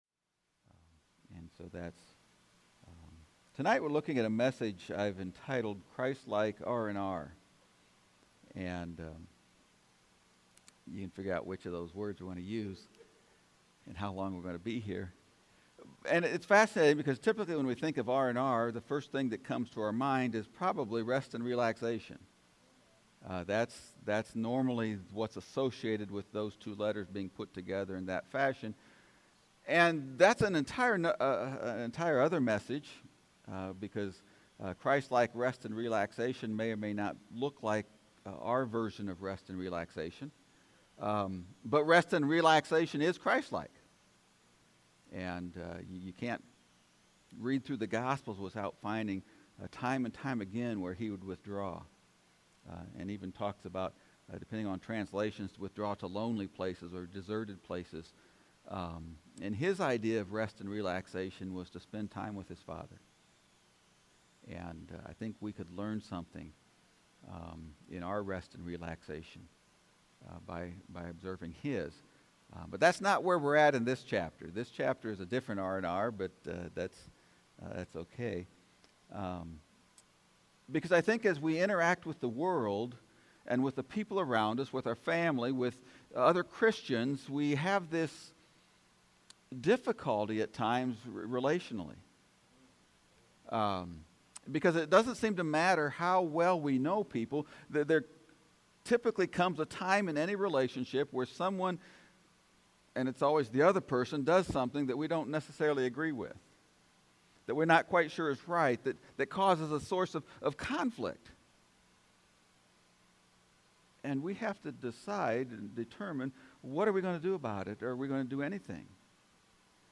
Sermon from the "Living Like Christ" series.